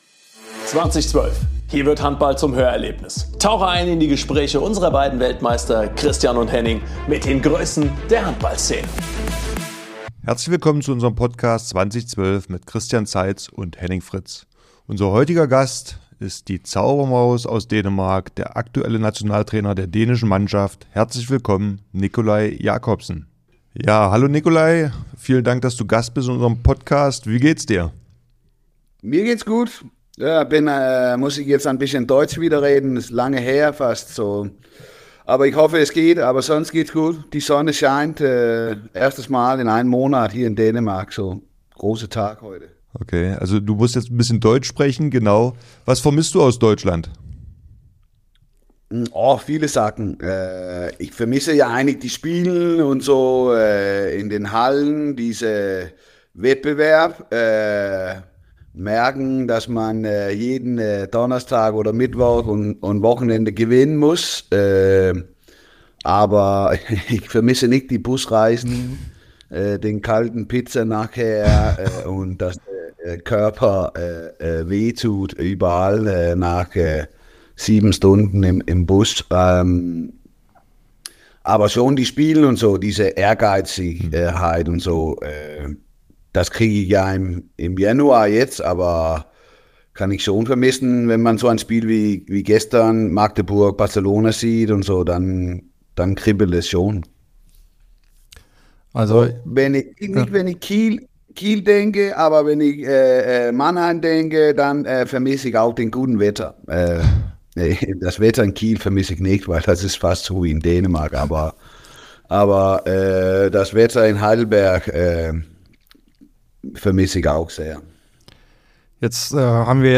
Herzlich willkommen zur zweiten Staffel des Podcasts "20/12" mit den Gastgebern Christian Zeitz und Henning Fritz!